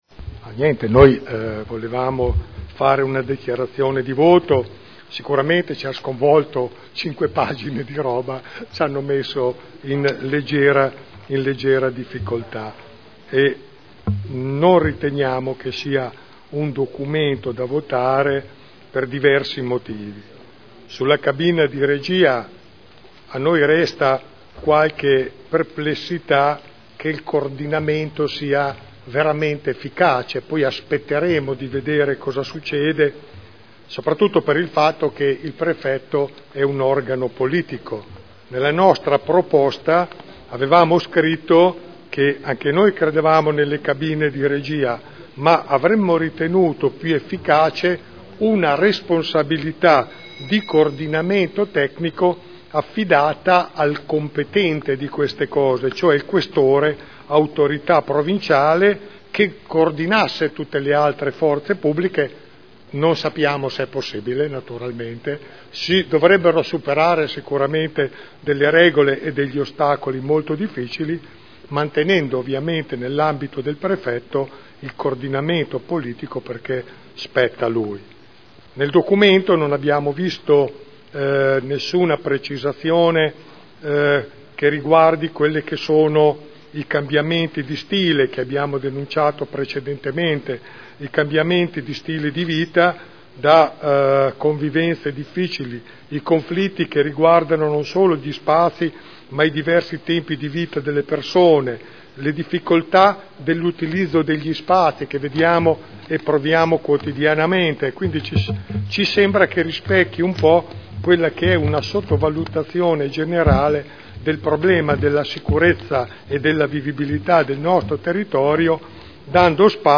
Dichiarazione di voto.